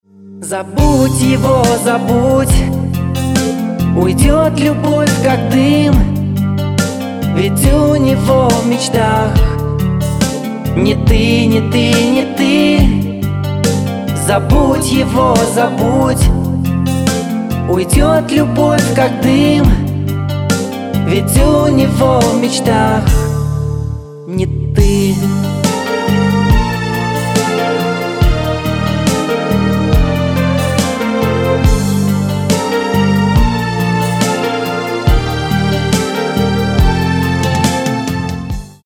мужской голос
грустные
медленные